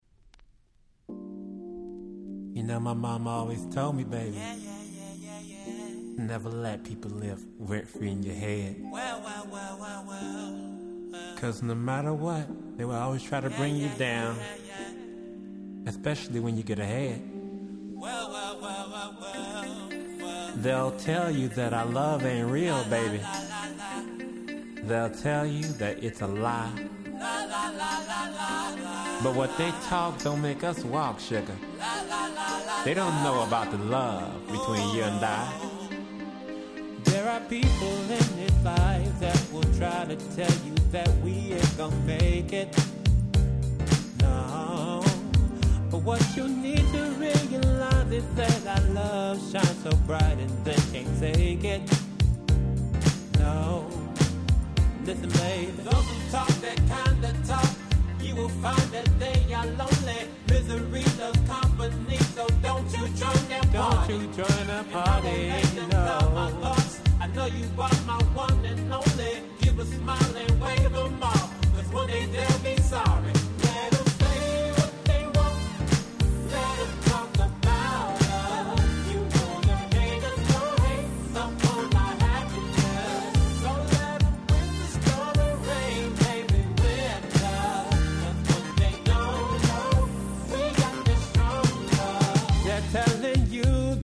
聴いていてワクワクしてしまう様な素敵なBoogieやModern Soulがてんこ盛りです！！